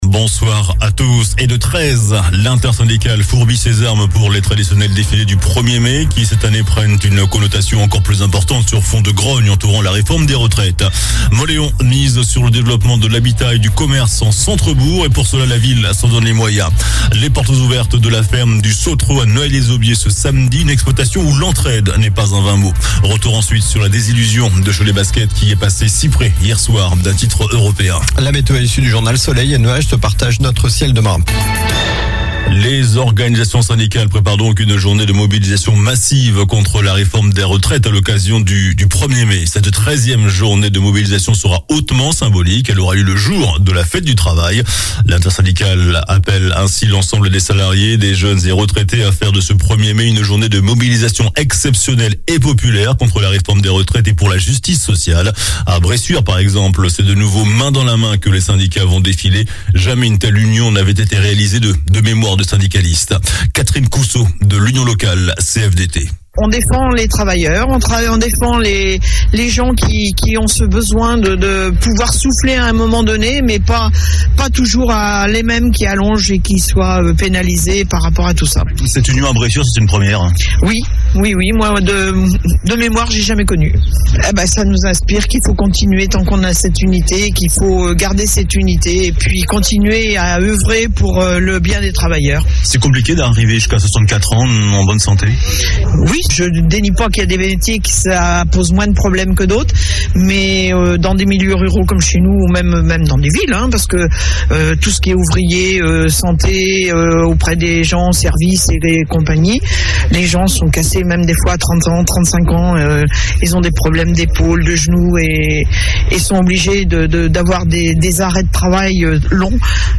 JOURNAL DU JEUDI 27 AVRIL ( SOIR )